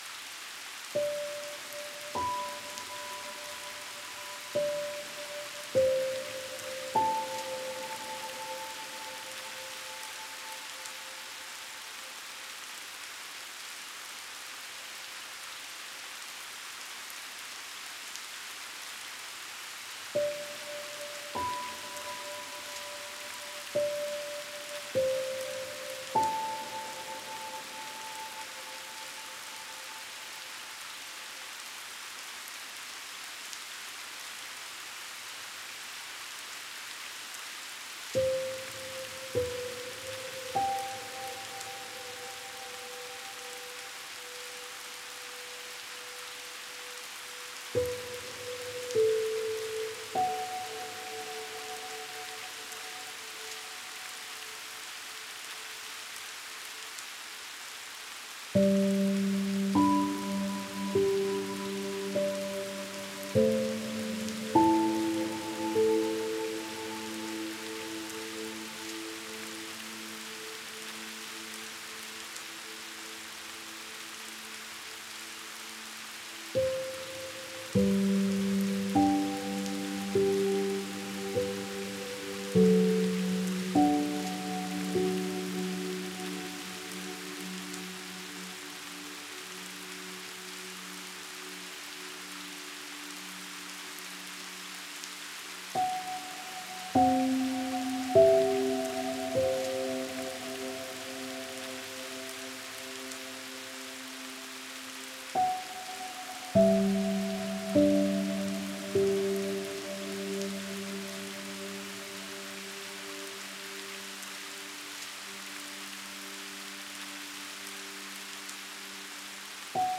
это легкая и мелодичная песня в жанре поп